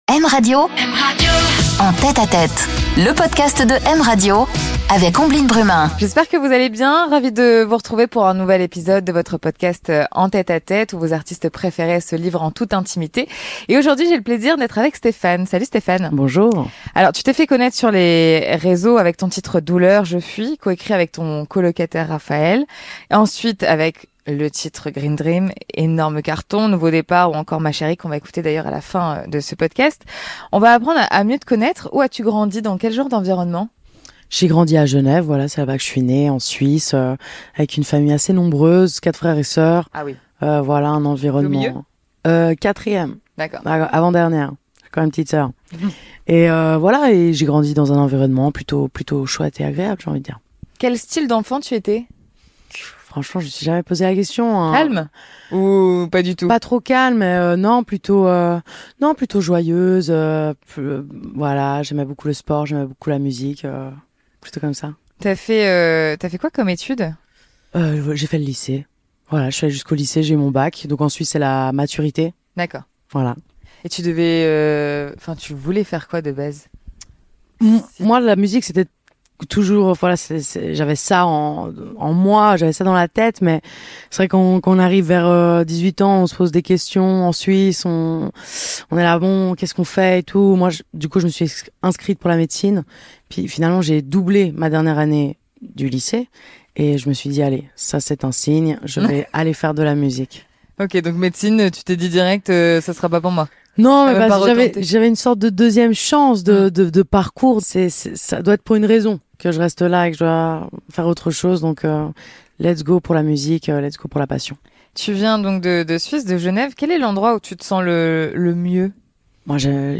Une interview en confidence, dans l'intimité des artistes